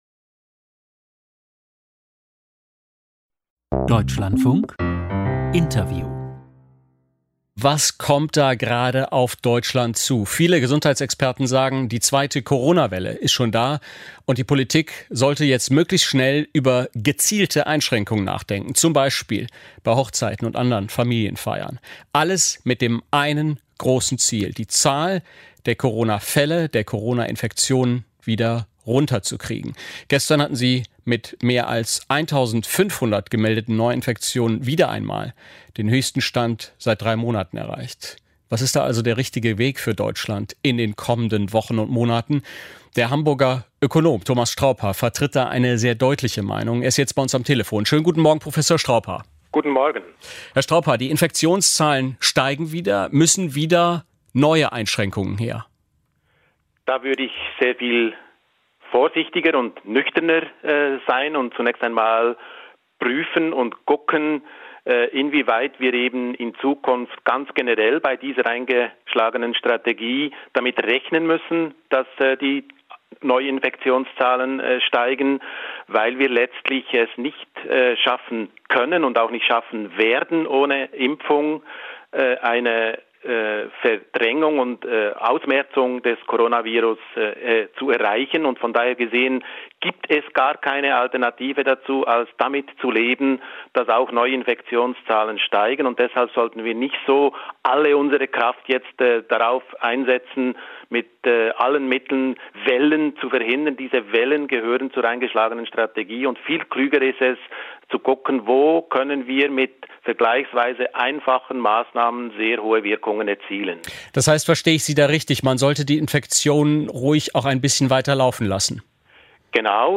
Hören Sie das Interview des Dlf vom 20.8.2020 mit Dr. Thomas Straubhaar: